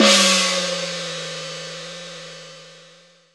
JJPercussion (248).wav